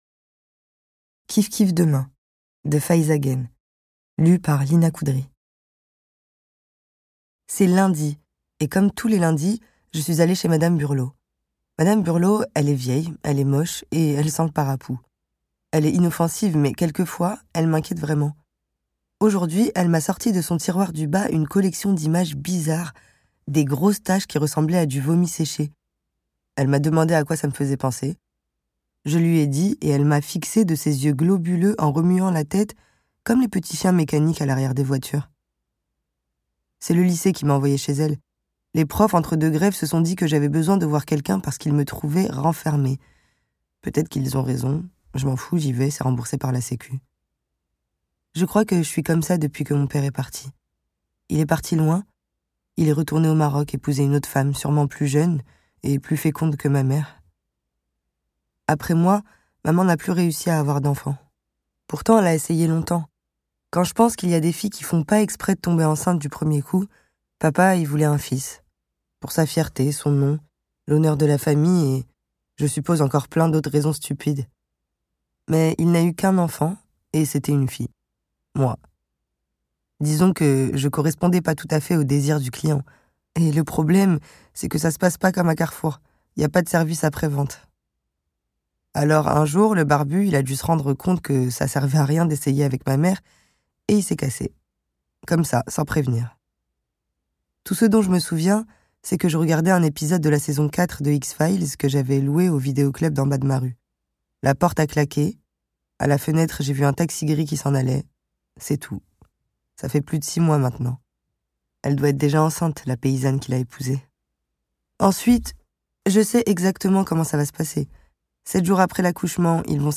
Extrait gratuit - Kiffe Kiffe demain de Faïza Guène, Lyna Khoudri